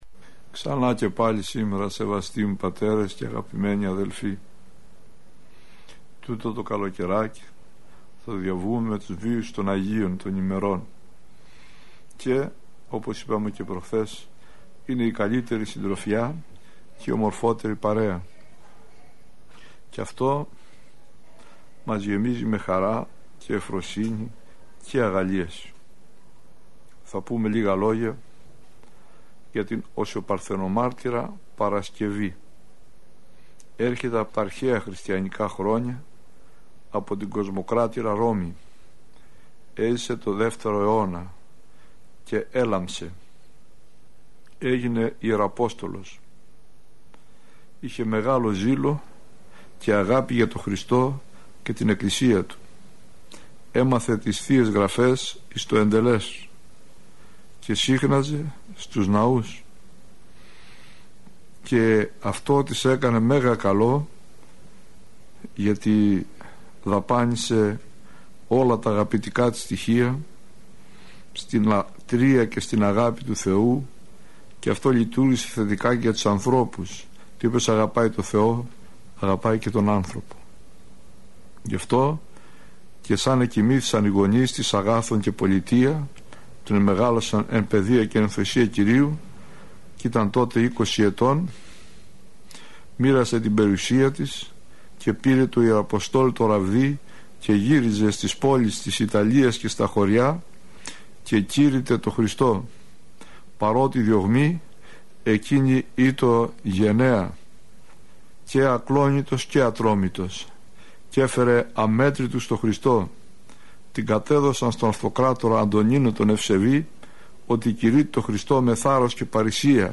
Πρόκειται για “σειρά” ομιλιών που μεταδόθηκαν από τον ραδιοσταθμό της Εκκλησίας της Ελλάδος.